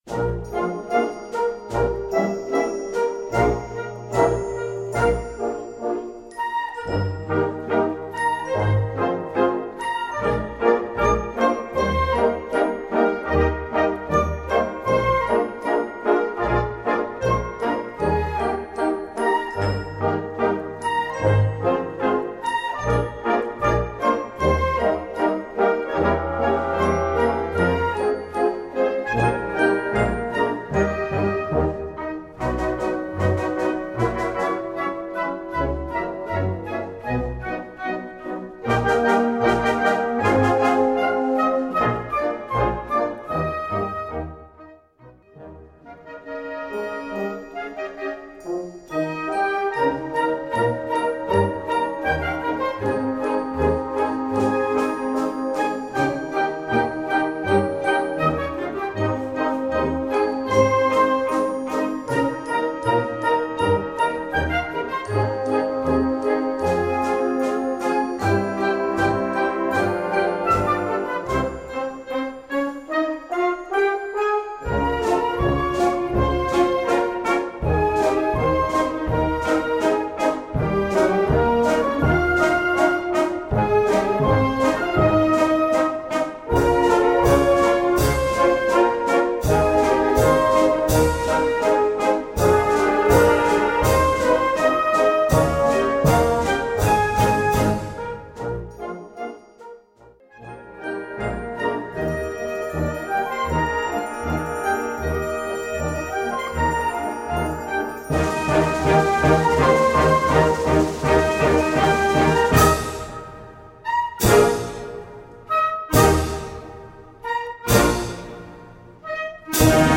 Gattung: Polka française
Besetzung: Blasorchester